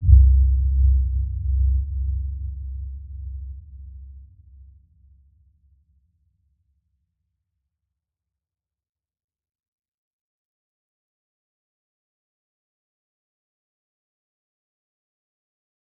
Dark-Soft-Impact-C2-mf.wav